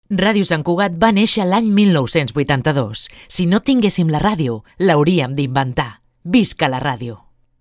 Enregistrament fet amb motiu del Dia mundial de la ràdio 2022.